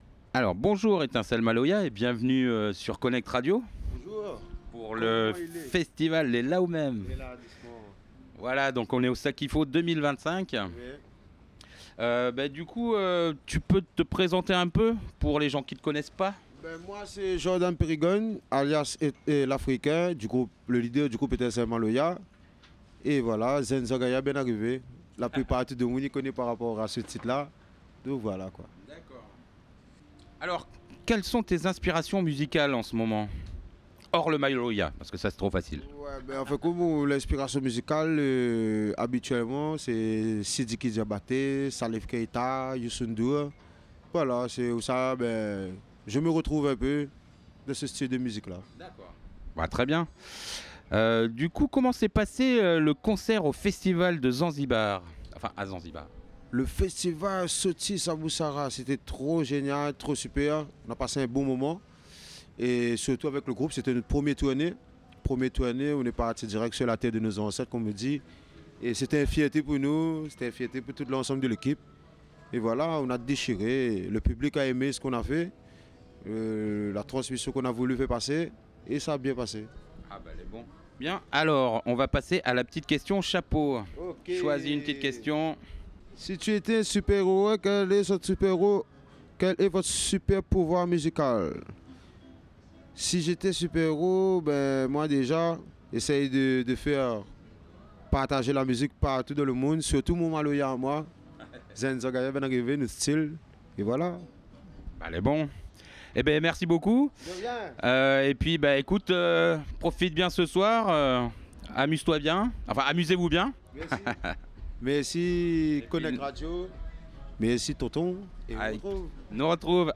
INTERVIEW: Stogie T